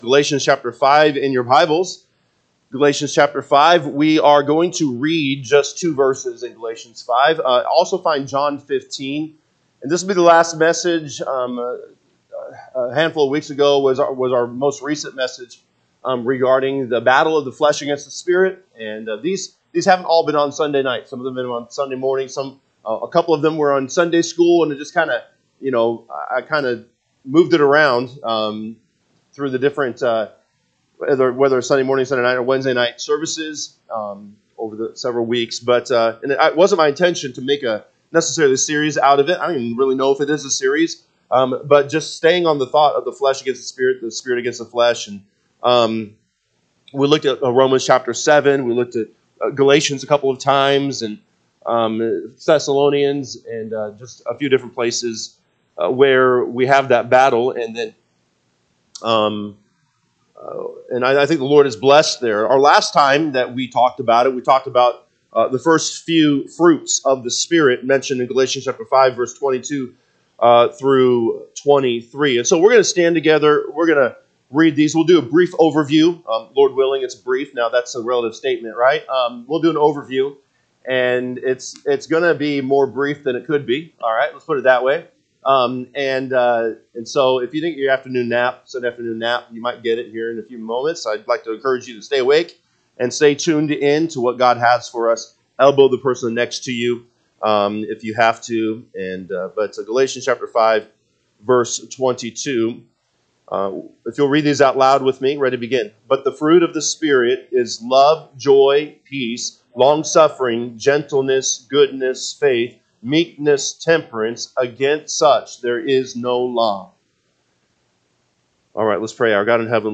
March 23, 2025 pm Service Galatians 5:22-23 (KJB) 22 But the fruit of the Spirit is love, joy, peace, longsuffering, gentleness, goodness, faith, 23 Meekness, temperance: against such the…
Sunday PM Message